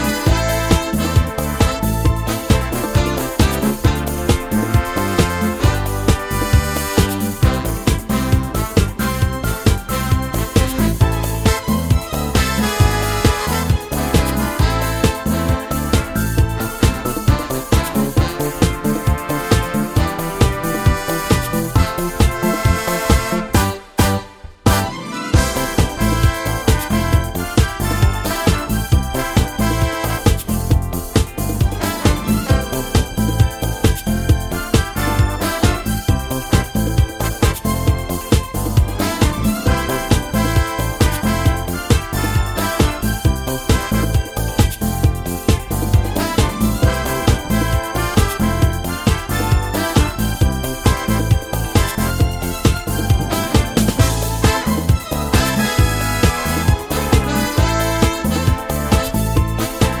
no Backing Vocals Disco 3:49 Buy £1.50